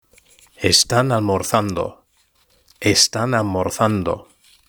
Lectura en voz alta: 3.2 Los medios de comunicación y la tecnología (H)